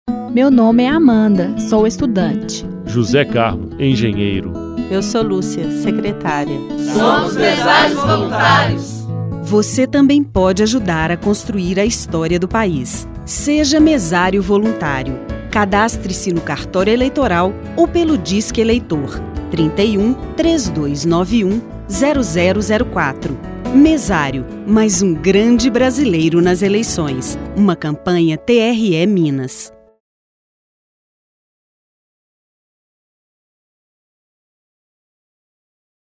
spot mesário voluntário